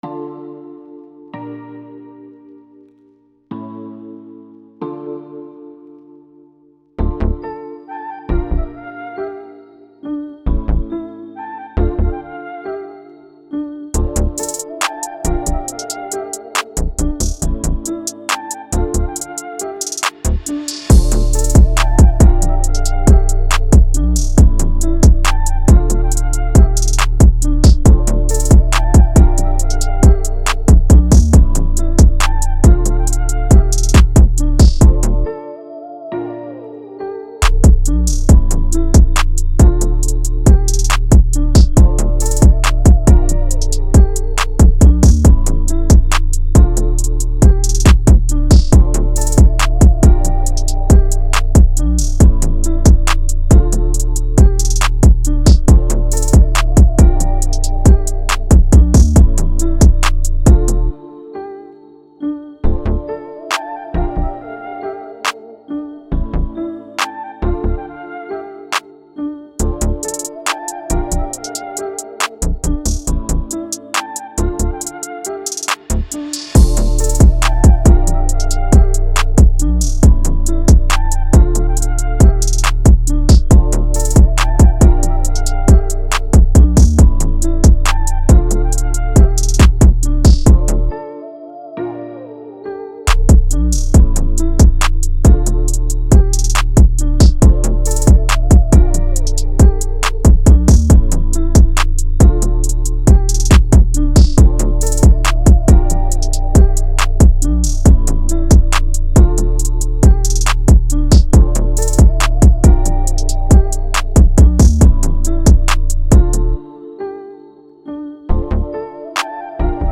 Hip Hop
D Min